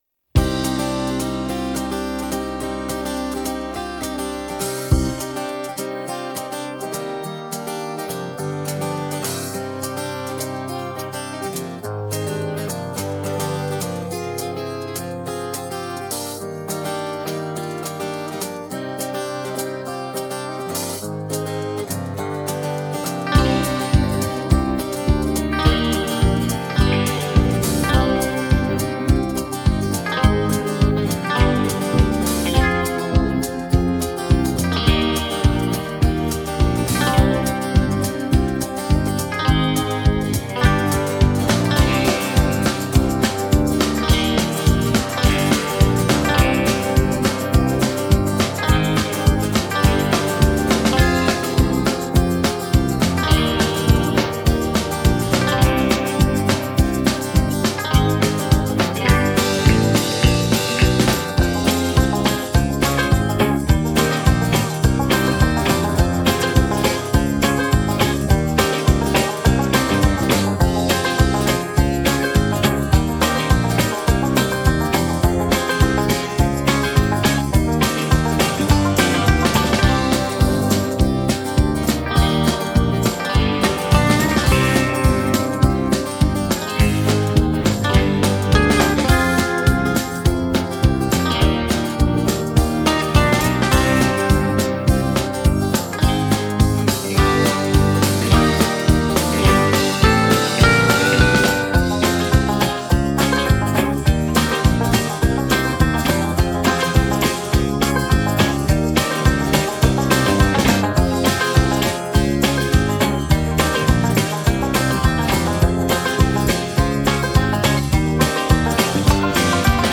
(минус)